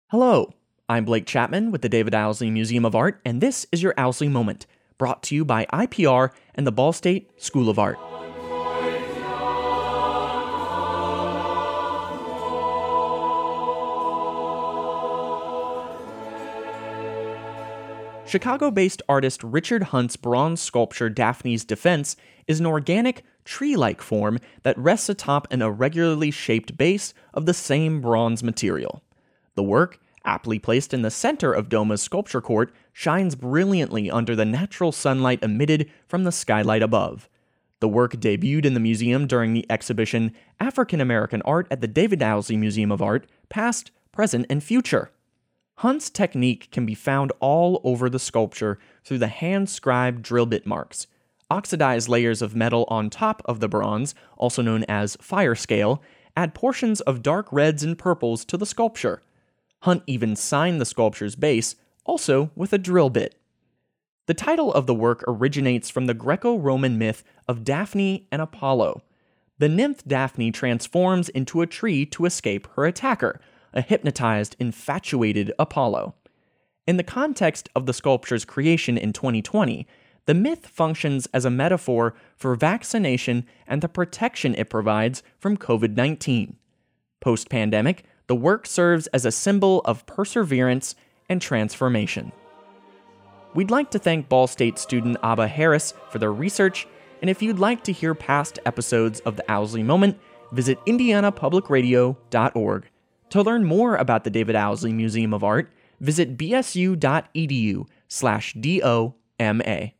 Music used in this episode:
Performed by Monteverdi-Chor Hamburg